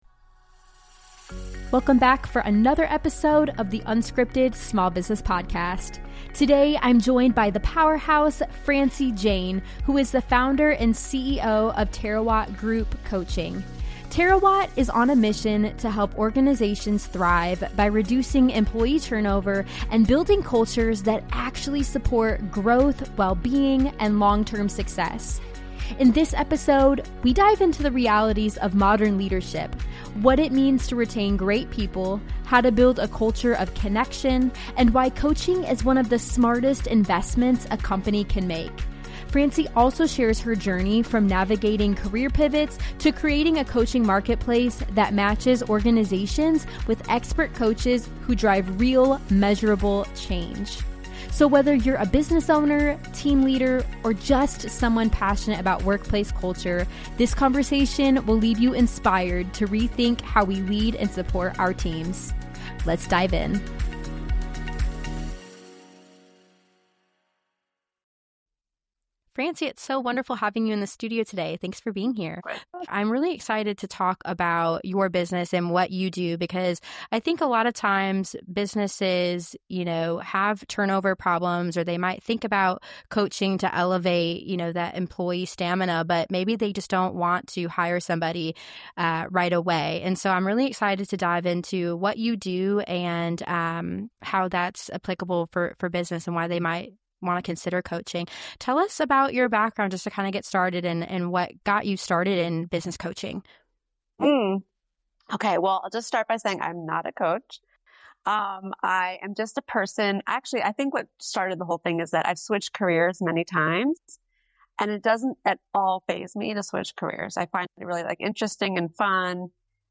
an illuminating conversation